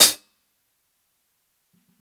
635_HH_LOUD.wav